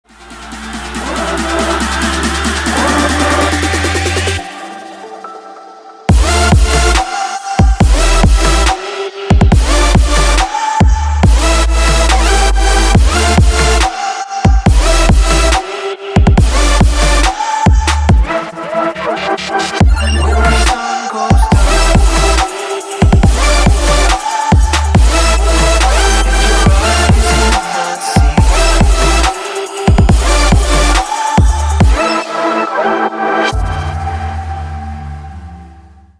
• Качество: 320, Stereo
Electronic